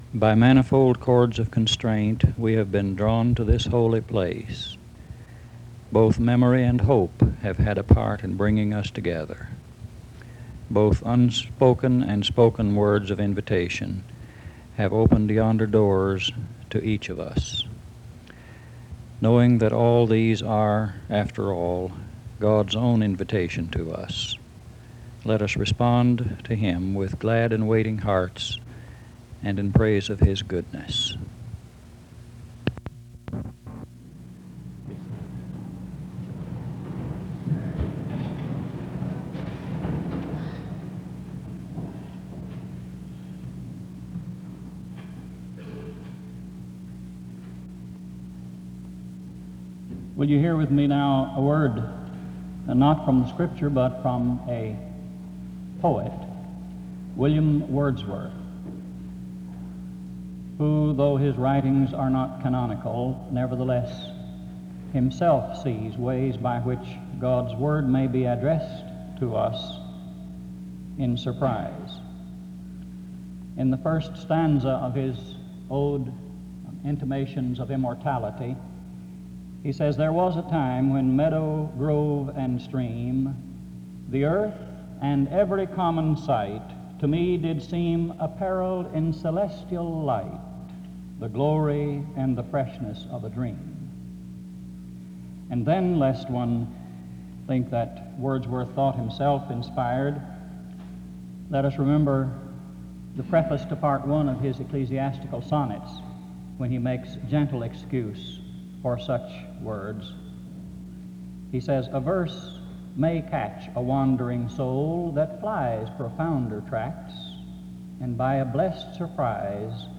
The service starts with a prayer from 0:00-0:32. A poem is read from 0:50-2:08. A prayer is offered from 2:10-6:04.